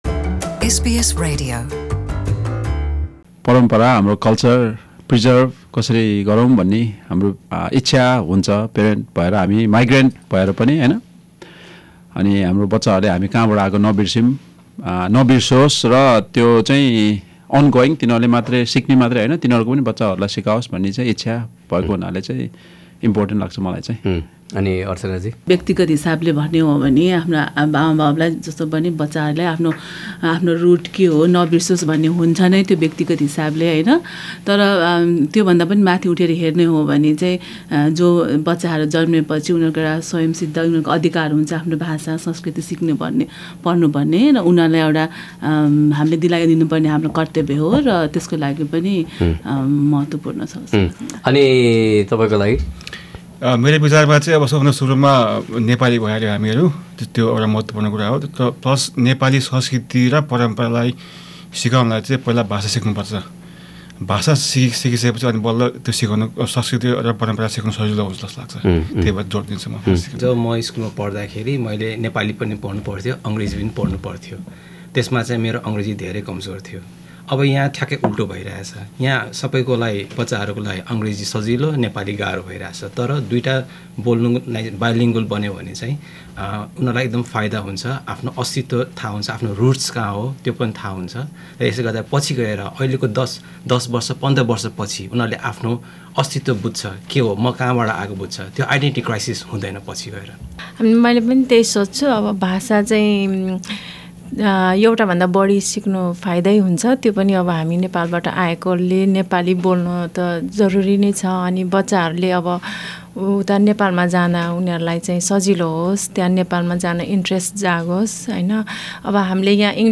शब्दमाला - नेपाली पोडकाष्ट शृंखला: भाग ३ - अभिभावकहरुसँगको कुराकानी